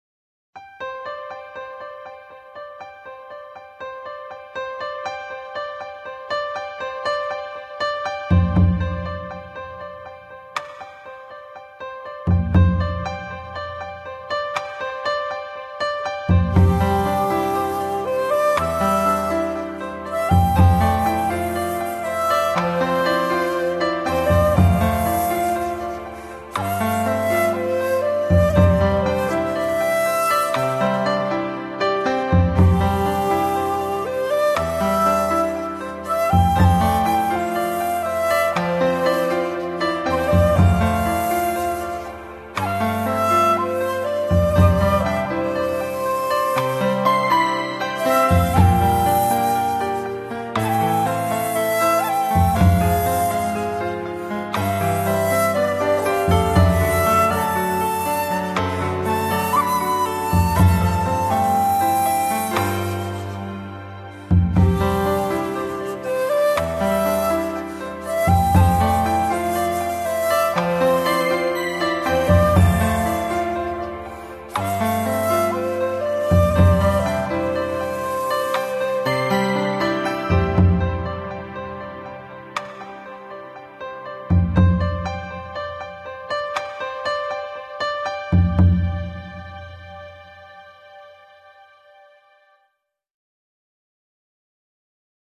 Очень мелодичная и красивая на мой вкус.